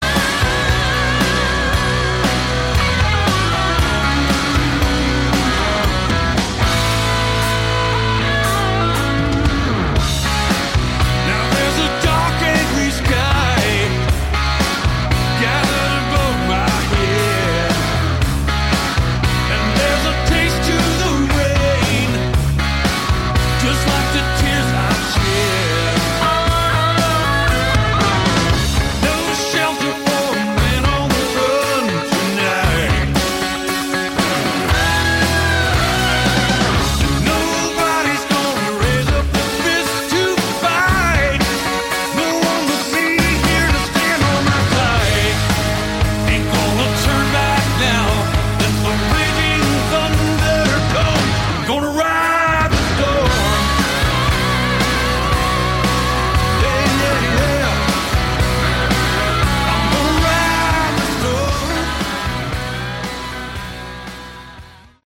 Category: AOR
keyboards